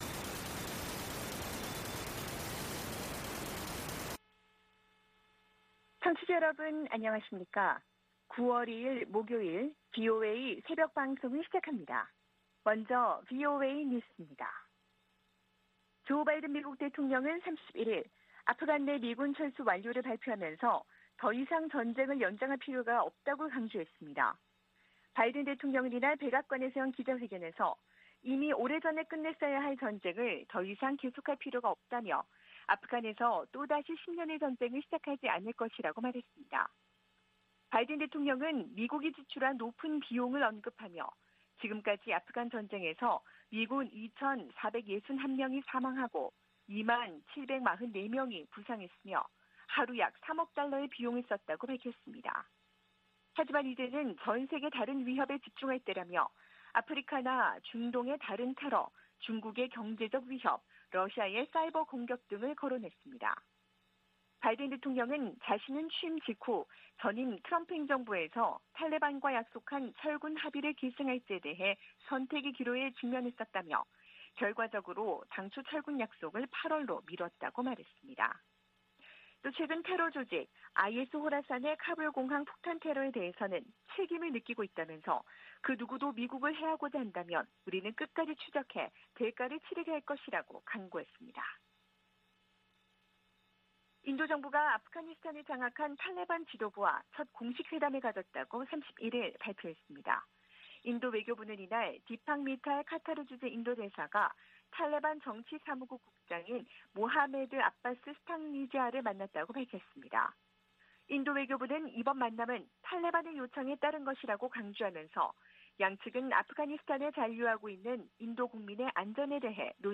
VOA 한국어 '출발 뉴스 쇼', 2021년 9월 2일 방송입니다. 미 연방 관보에 8월 31일 현재 북한 여행금지 조치를 연장한다는 내용이 게시되지 않았습니다. 백악관은 북한 핵 문제와 관련해 대화의 문이 열려 있으며 조건 없는 만남 제안이 여전히 유효하다고 밝혔습니다.